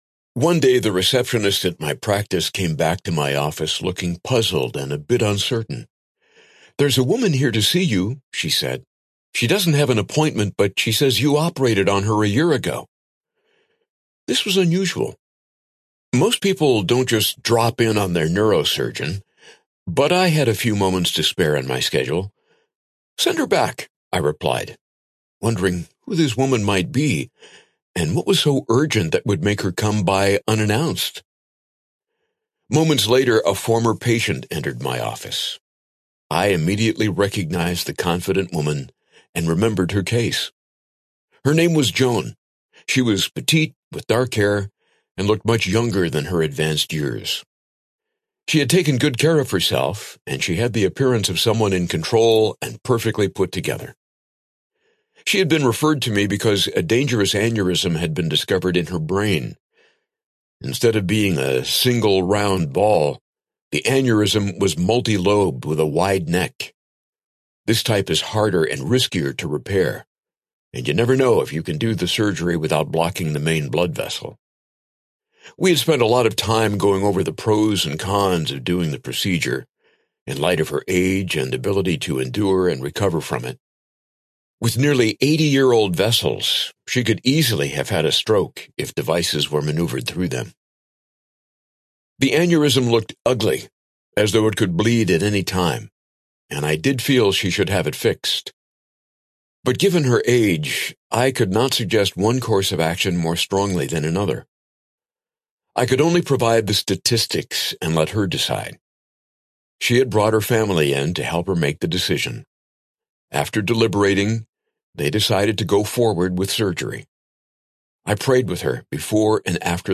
Gray Matter Audiobook
Narrator
8.5 Hrs. – Unabridged